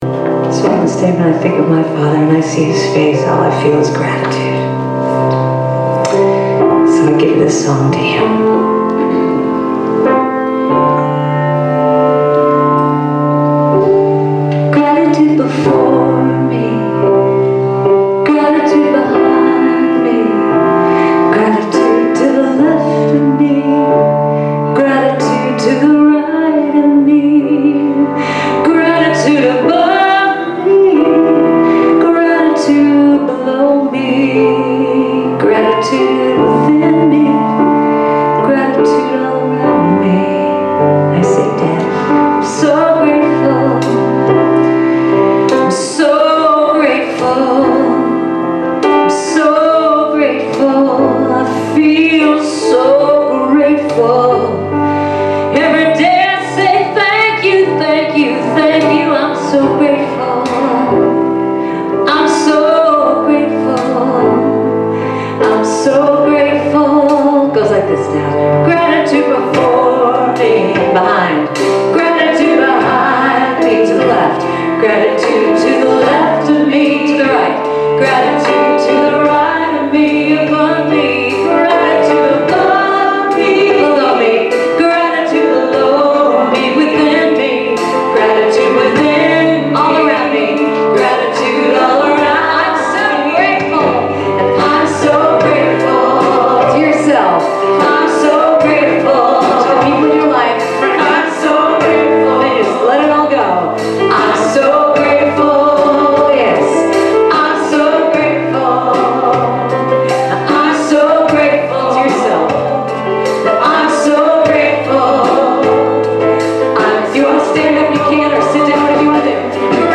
Minister Emeritus Series: Sermons 2023 Date